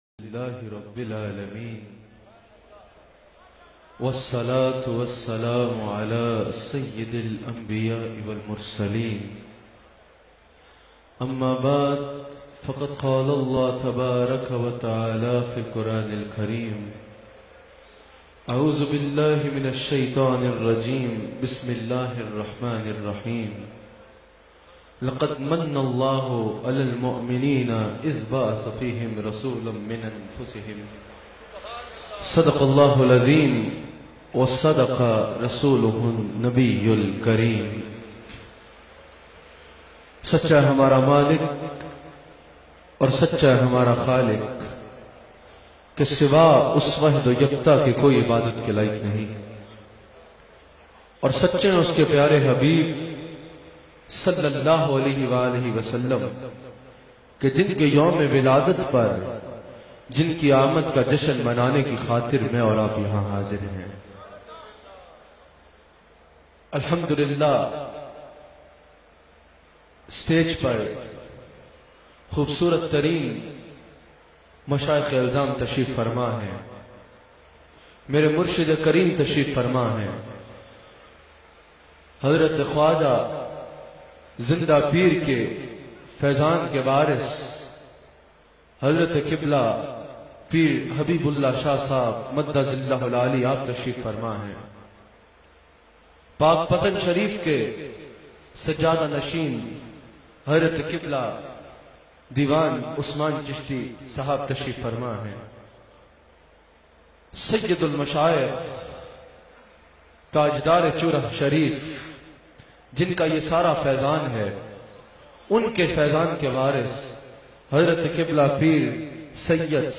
Jashan E Amad E Rasool bayan mp3